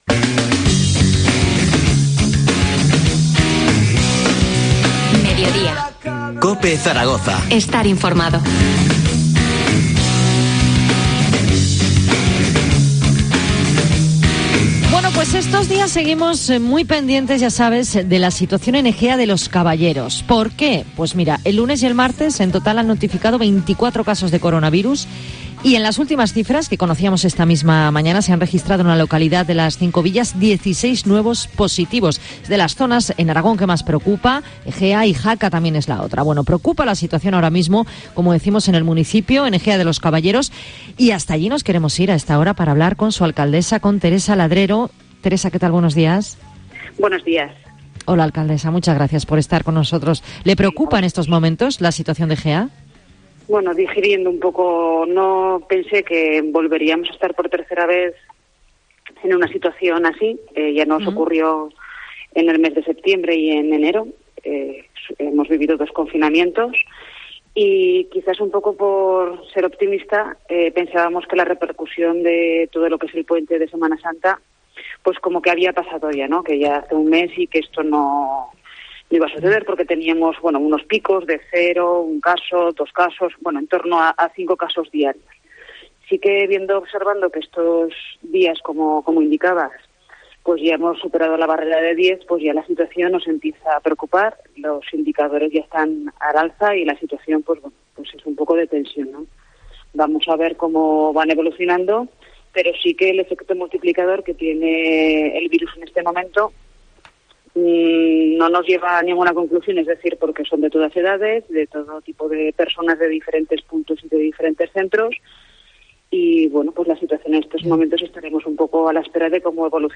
Entrevista a Teresa Ladrero, alcaldesa de Ejea de los Caballeros. 28-04-21